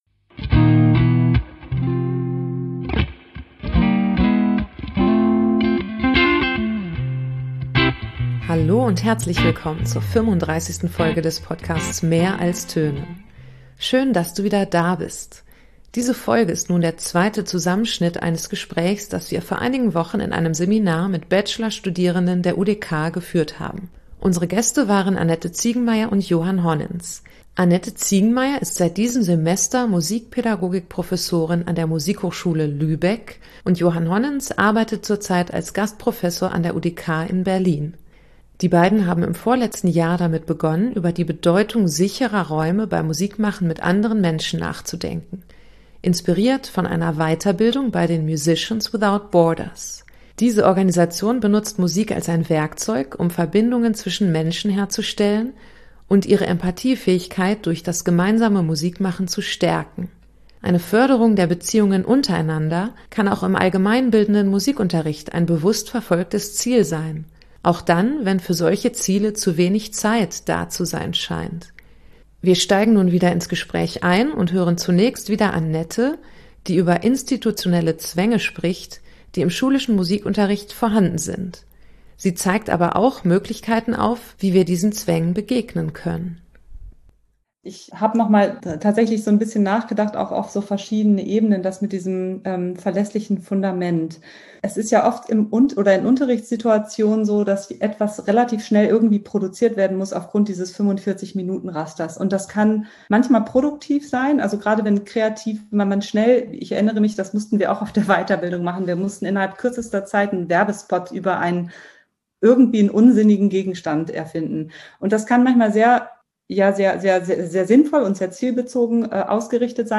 In diesem zweiten Teil unseres Gesprächs im Musikpädagogik-Seminar sprechen wir darüber, welche pädagogischen Fähigkeiten anderen Menschen dabei helfen können, sich in künstlerischen Prozessen zu öffnen und Dinge auszuprobieren – vom Vertrauen in die Fähigkeiten anderer Menschen, über die Bedeutung von Erfolgserlebnissen, bis hin zu Frage, wie wir anderen bei kreativen Aufgaben eine Struktur und Orientierung geben können. Es kommt auch die Frage auf, ob das musikpädagogische Ziel anderen Menschen sichere Räume anzubieten, vielleicht kulturell bedingt ist.